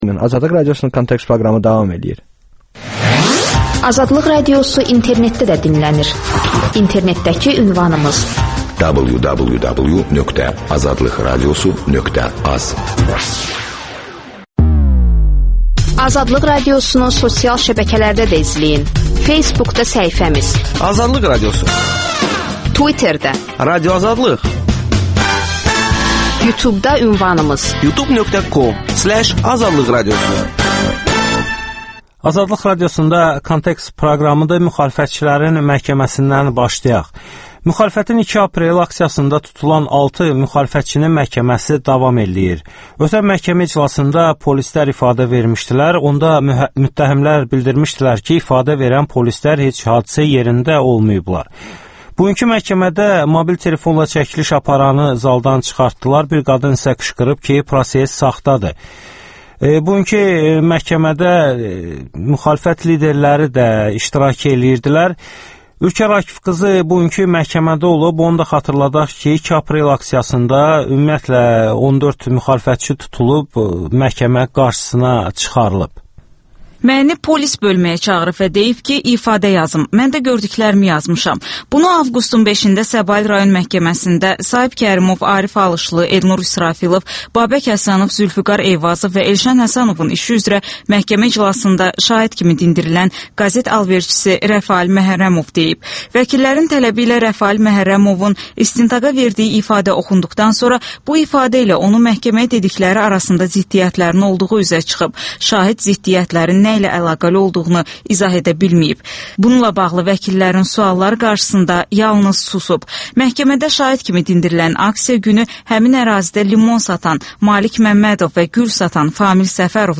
Müsahibələr, hadisələrin müzakirəsi, təhlillər